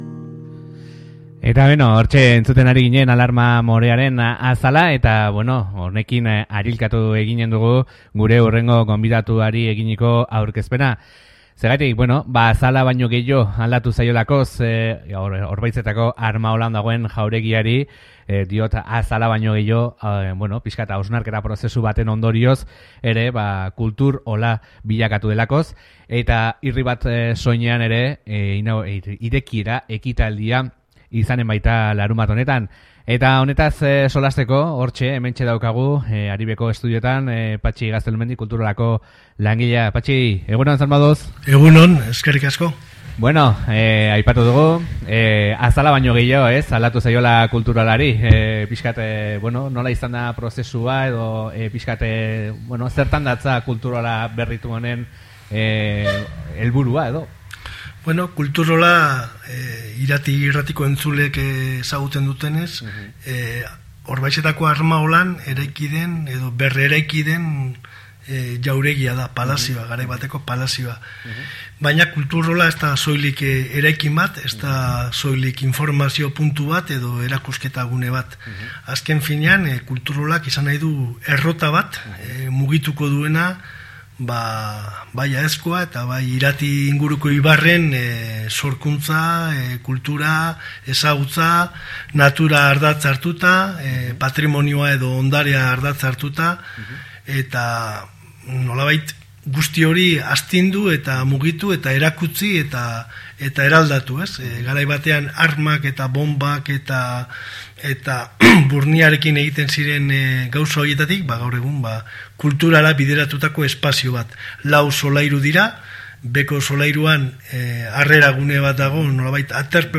egon gara solasean